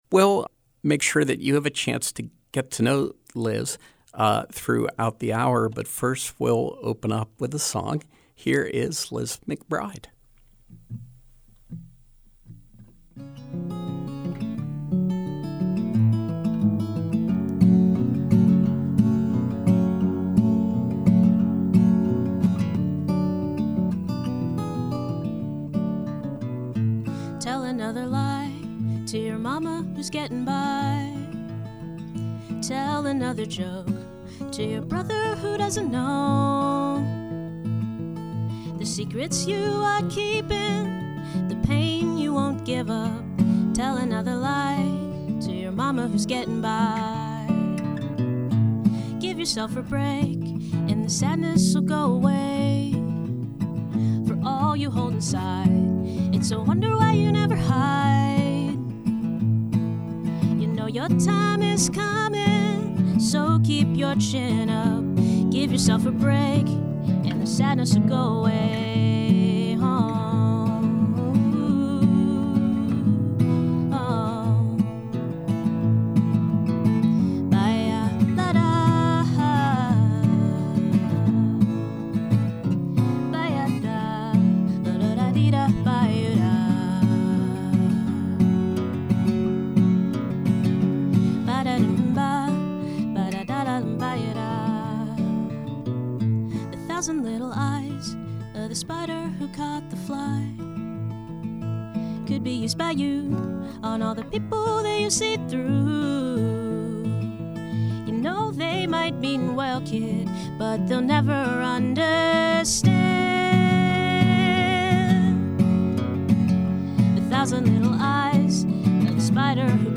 Live Music
with live music and conversation